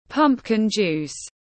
Nước ép bí đỏ tiếng anh gọi là pumpkin juice, phiên âm tiếng anh đọc là /ˈpʌmp.kɪn ˌdʒuːs/
Pumpkin juice /ˈpʌmp.kɪn ˌdʒuːs/